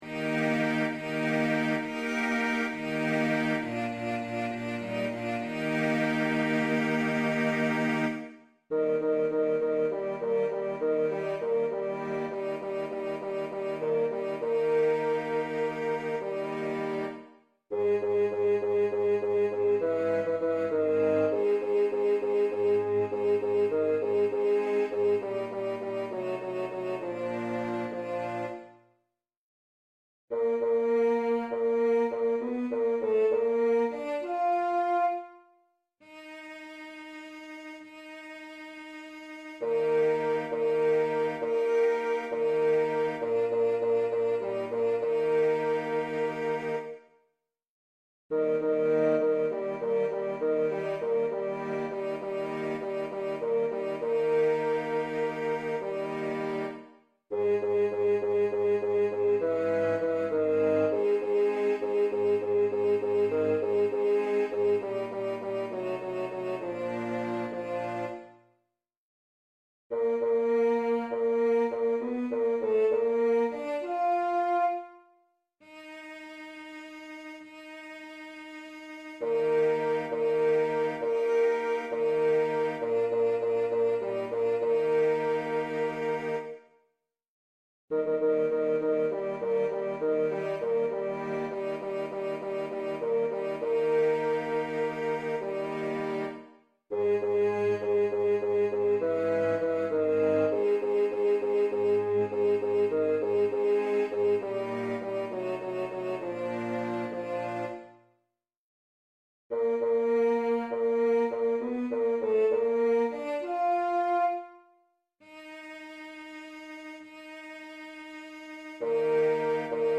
luetzows-wilde-jagd-einstudierung-bass-1.mp3